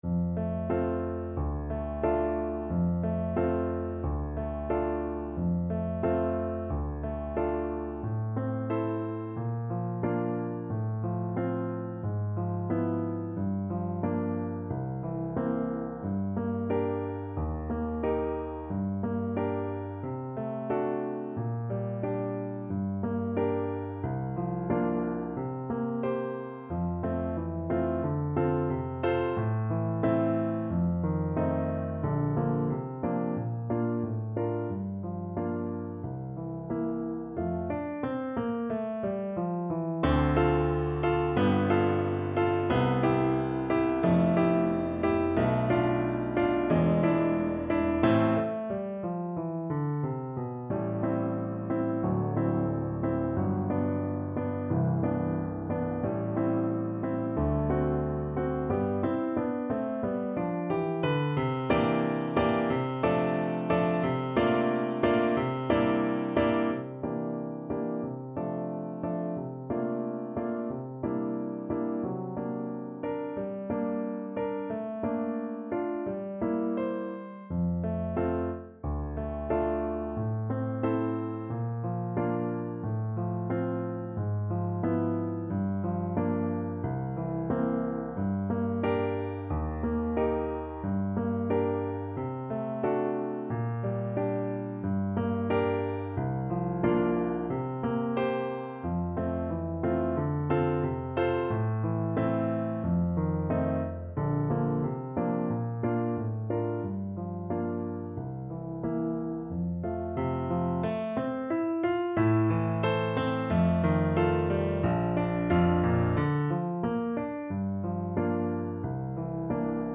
Classical
~ = 100 Allegretto con moto =90